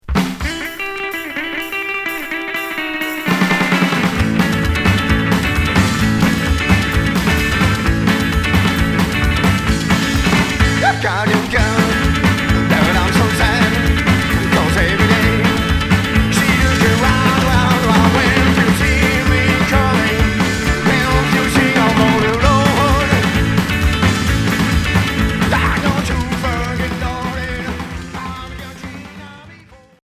Rockabilly Unique 45t retour à l'accueil